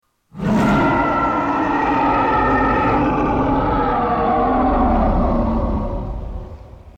Звук рычания Кинг Конга